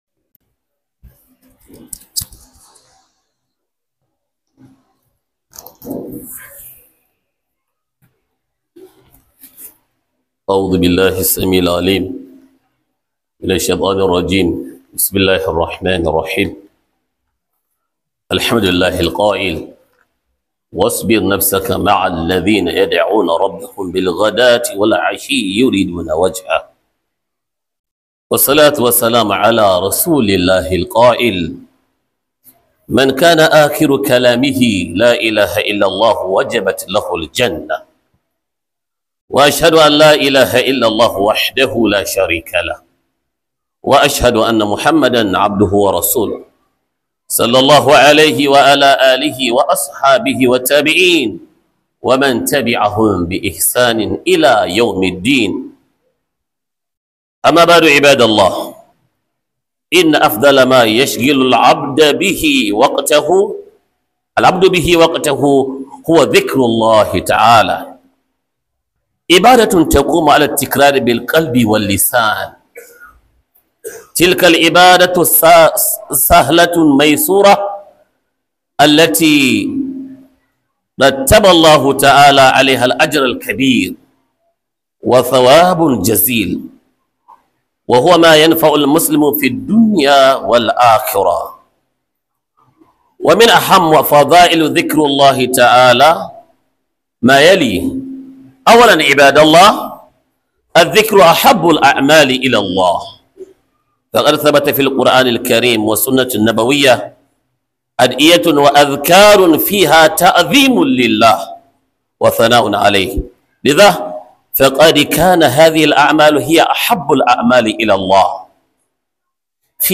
Huduba - Ambaton Allah